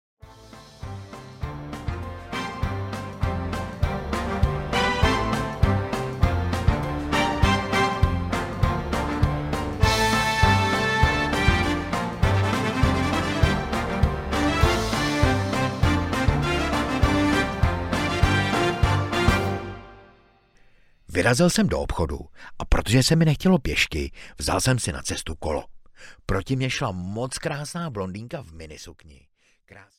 Tam u nás v Podžlebí audiokniha
Ukázka z knihy
tam-u-nas-v-podzlebi-audiokniha